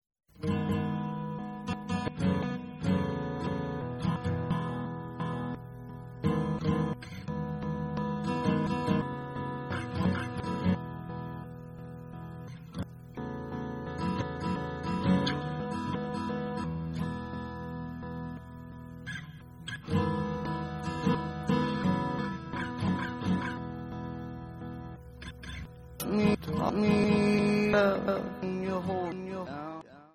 Blues
Pop
Rock
Roots